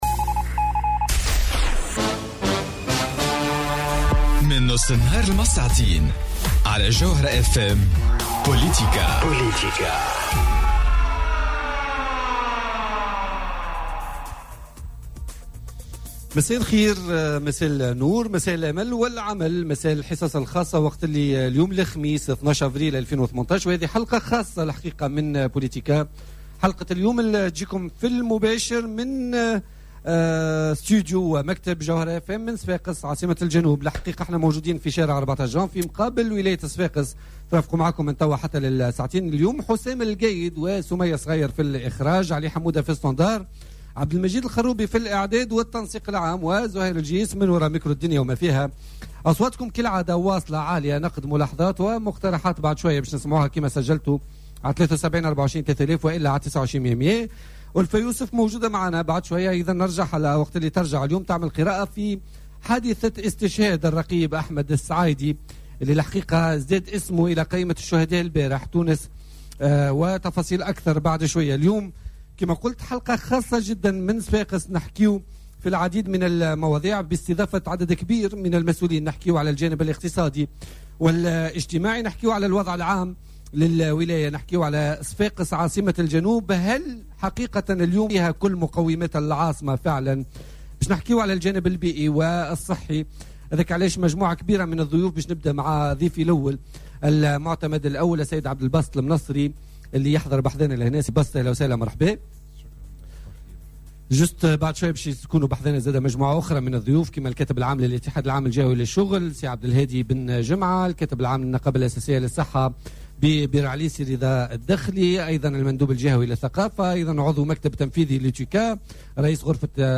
حلقة خاصة من صفاقس..